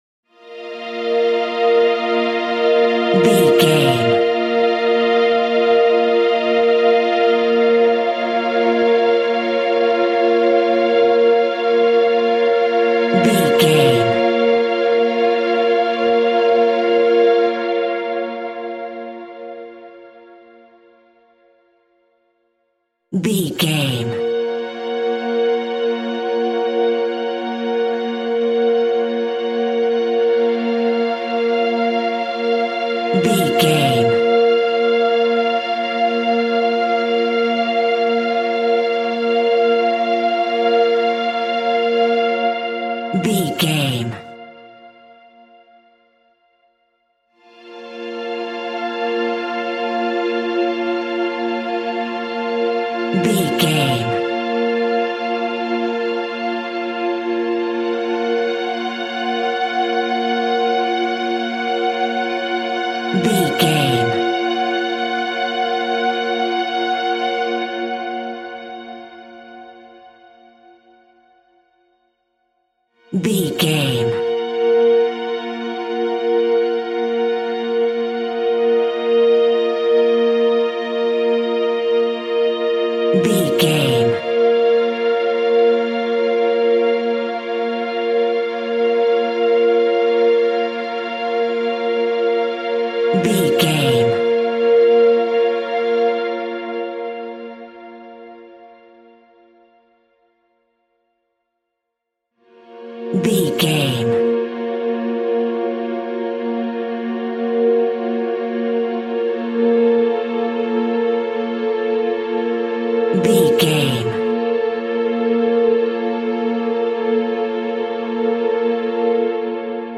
Slow Haunting Strings.
Aeolian/Minor
ominous
eerie
synthesizer
pads